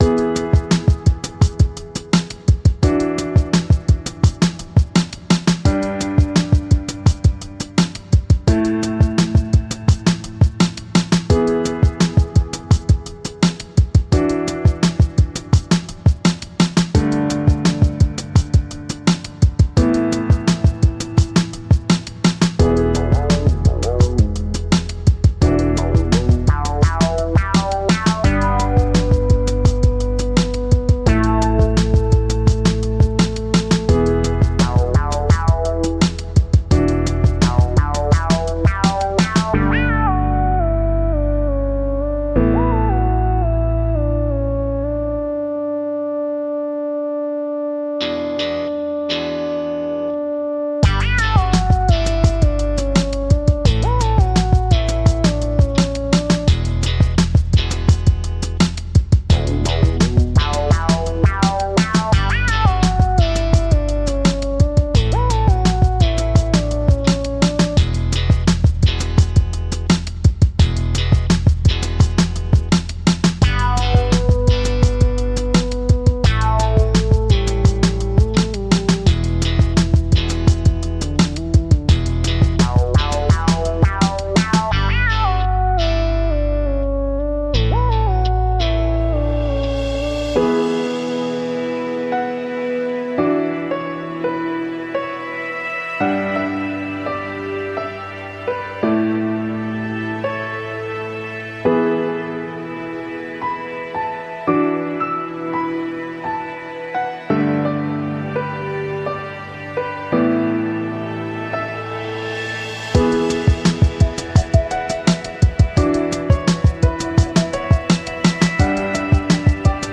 Elating and inspirational soundscapes for daydreaming.